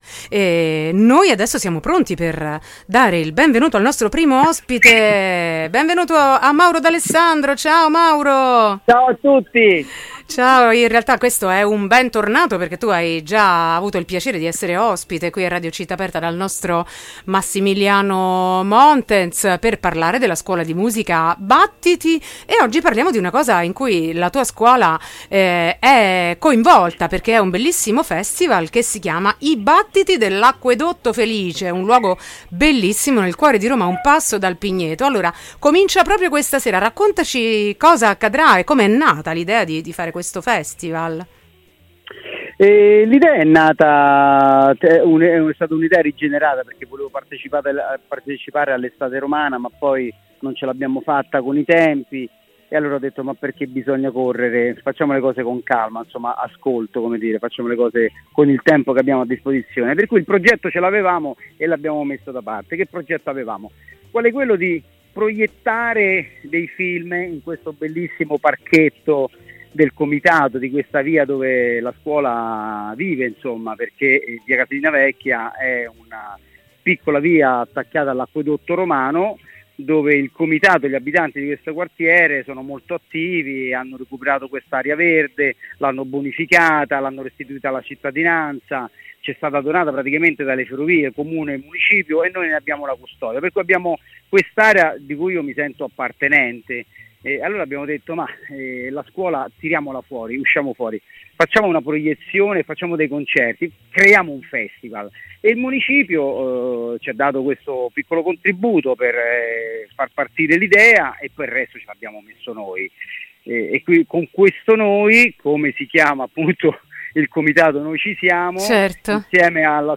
intervista-festival-battiti.mp3